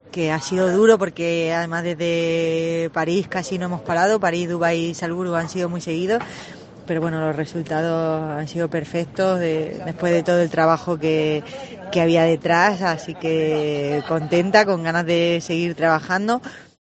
En declaraciones a la Cadena COPE, Sánchez ha explicado que “ha sido duro, pero el resultado ha merecido la pena. Después de todo el esfuerzo realizado, estoy muy contenta y con muchas ganas de seguir trabajando” (escucha el audio).